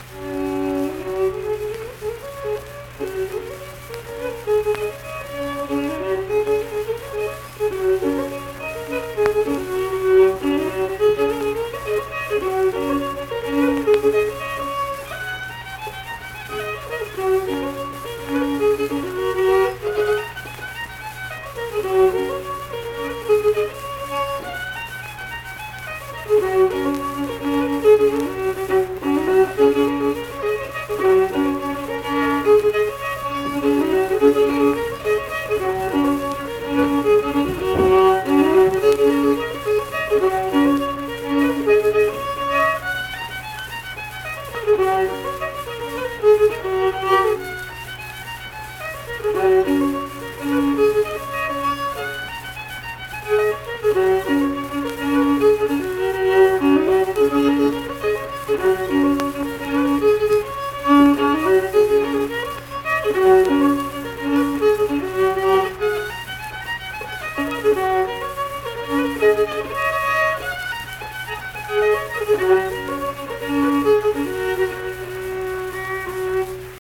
Unaccompanied fiddle music
Performed in Ziesing, Harrison County, WV.
Instrumental Music
Fiddle